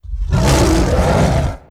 dragon.wav